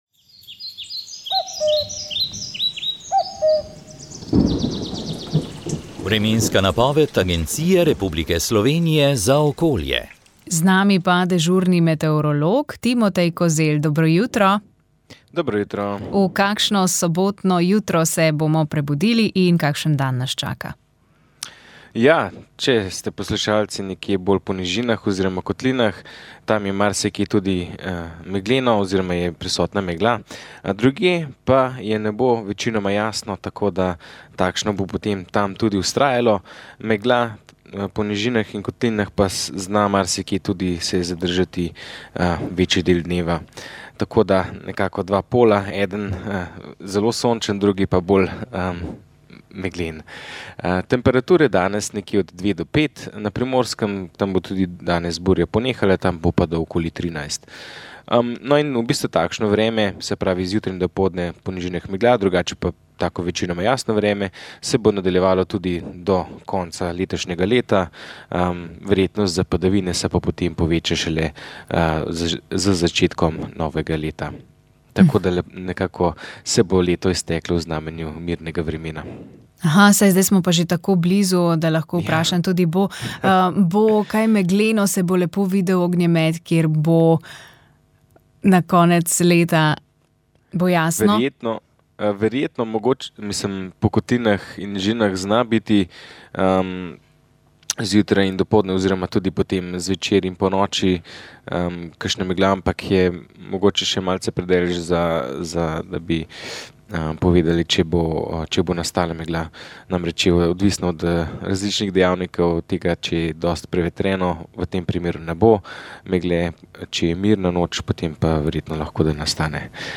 Duhovni nagovor
Duhovni nagovor je pripravil mariborski nadškof metropolit msgr. Alojzij Cvikl.